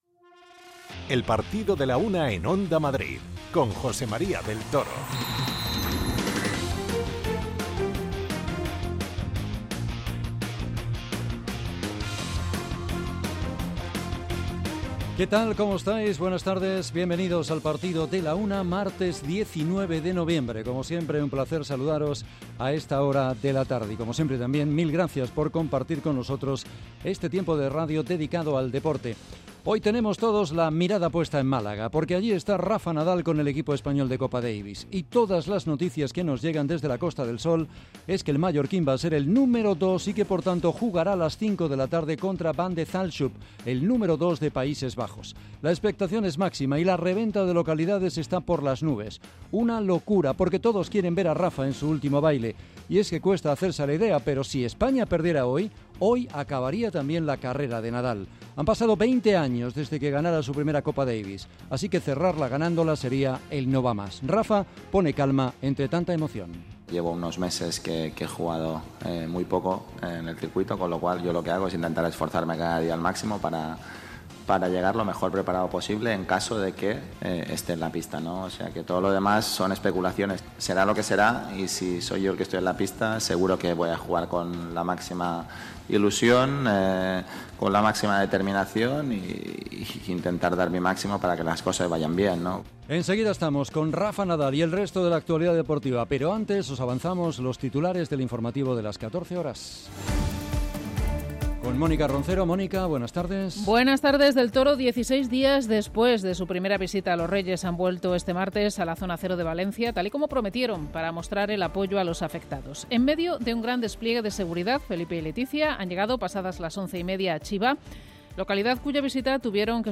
Escuchamos al mallorquín y conectamos con Málaga donde se está viviendo una verdadera locura ante la presencia de Rafa en su última competición.